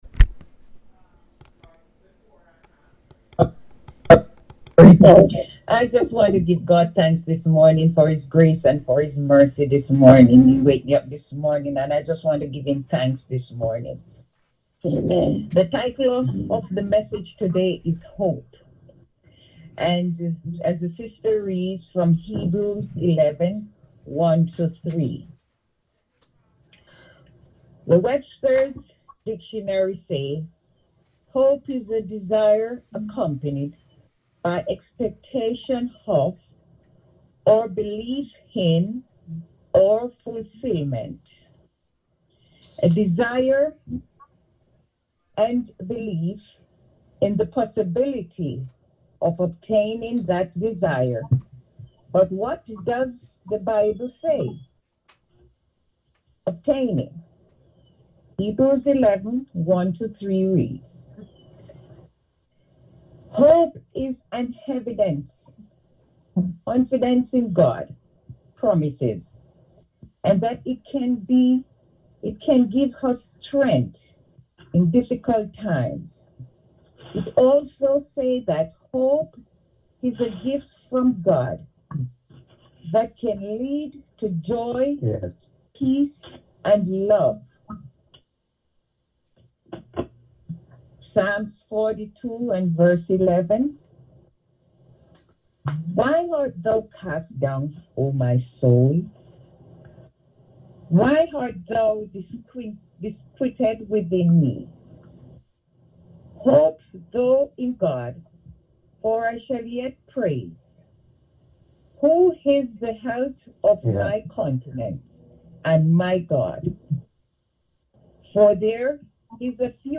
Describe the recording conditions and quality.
Women's Service